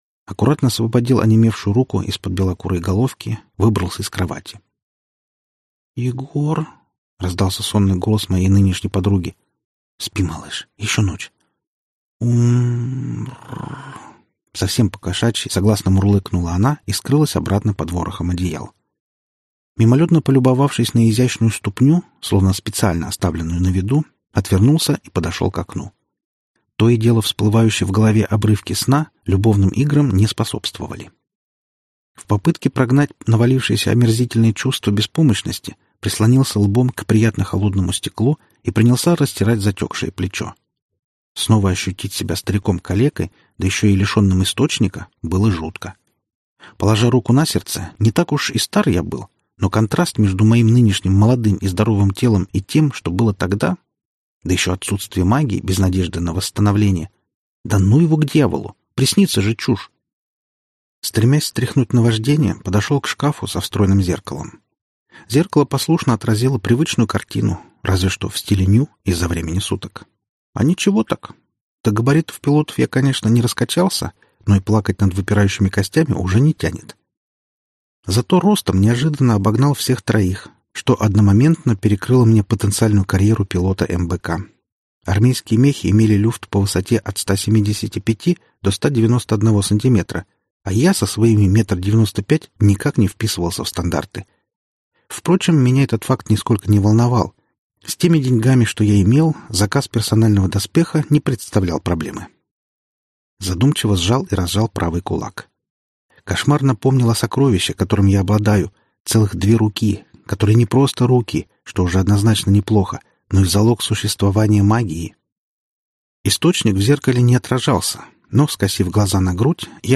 Аудиокнига Видящий. Небо на плечах | Библиотека аудиокниг